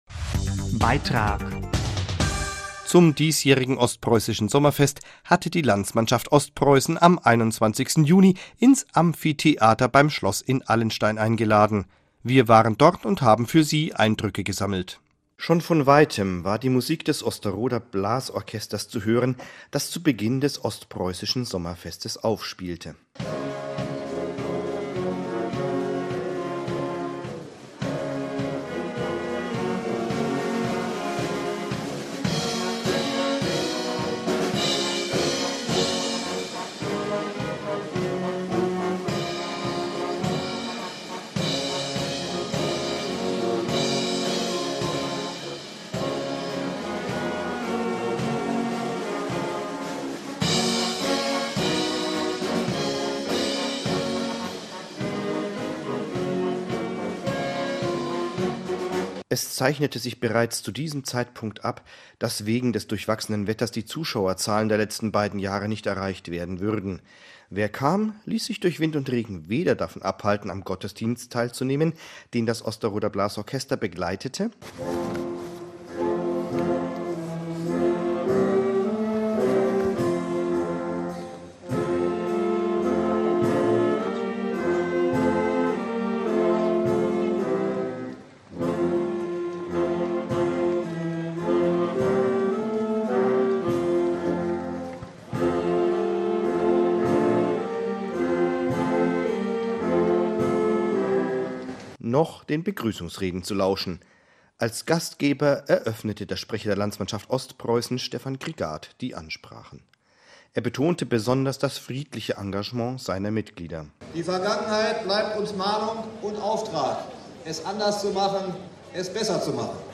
29.06.2014 00:00 Ostpreußisches Sommerfest 2014 11:12 Treffen evangelischer Chöre 00:00 festyn letni Prusach Wschodnich 2014 11:12 spotkanie chórów ewangelickich